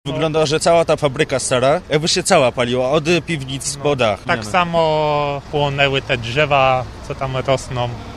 Nazwa Plik Autor Mówią świadkowie pożaru pustostanu na Polesiu audio (m4a) audio (oga) Z ogniem walczyło dziesięć zastępów straży pożarnej.